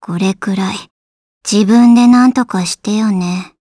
Gremory-Vox_Victory_jp.wav